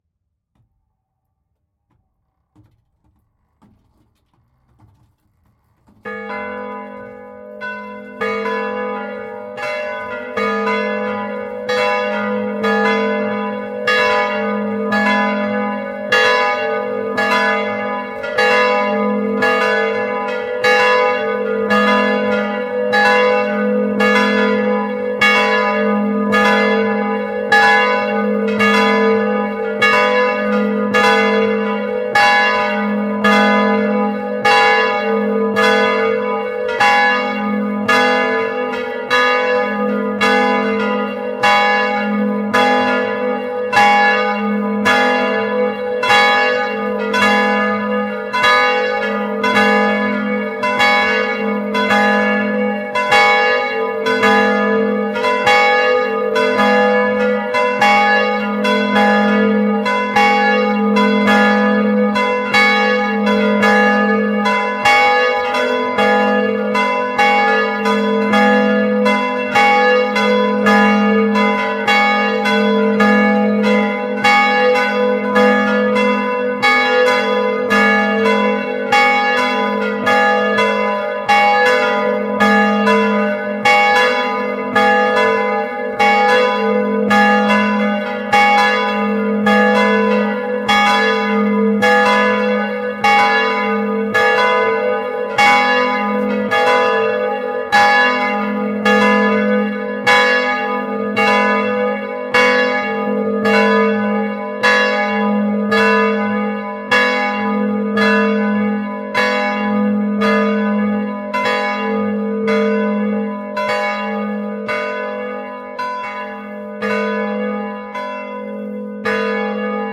Eglise de Soye
Deux cloches Slégers et Causard classiques, une Baron de Rosée.
Note et harmoniques : Hum : 250 Hertz. Prime : 553 Hertz. Tierce : 604 Hertz. Quint : 782 Hertz. Nominal : 999 Hertz. Superquint : 1497 Hertz. Oct nom : 2068 Hertz. Nombre d'harmoniques détectées : 10.
Note et harmoniques : Hum : 237 Hertz. Prime : 503 Hertz. Tierce : 576 Hertz. Quint : 706 Hertz. Nominal : 950 Hertz. Superquint : 1420 Hertz. Oct nom : 1963 Hertz. Nombre d'harmoniques détectées : 12.
Note et harmoniques : Hum : 207 Hertz. Prime : 448 Hertz. Tierce : 498 Hertz. Quint : 649 Hertz. Nominal : 822 Hertz. Superquint : 1229 Hertz. Oct nom : 1699 Hertz. Nombre d'harmoniques détectées : 23.
Vous pouvez écouter ci-dessous la volée (2,28mn) :